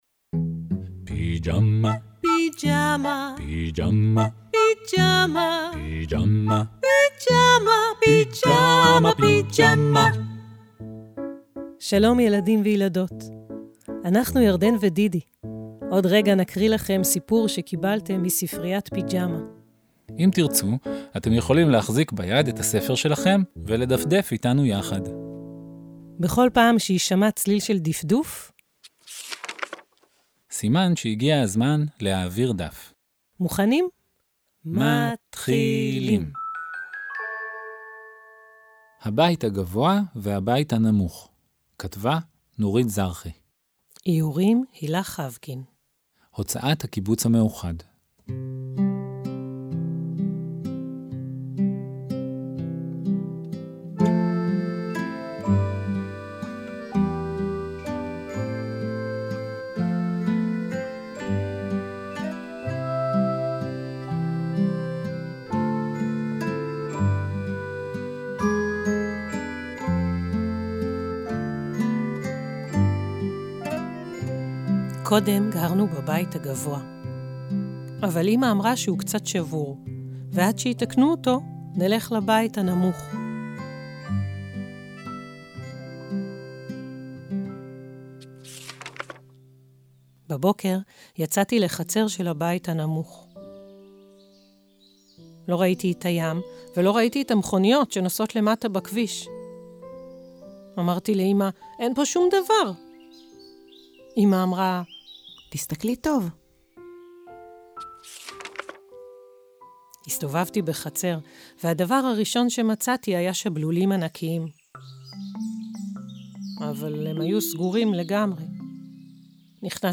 מוזיקה ונגינה
חליל